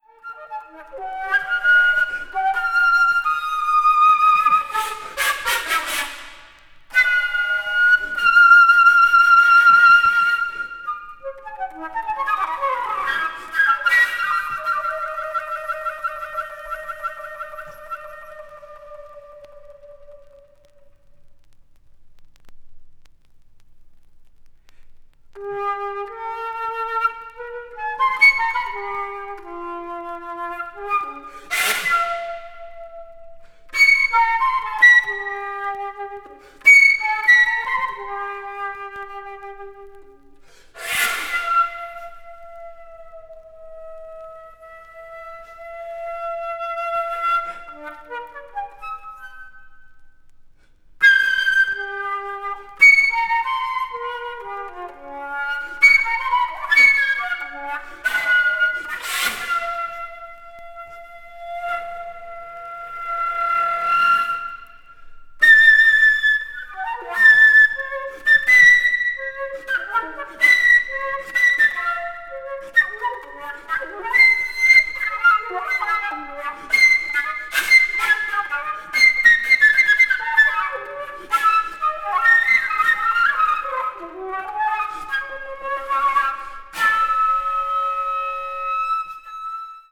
わずかにチリノイズが入る箇所あり
高域の金属音が飛散する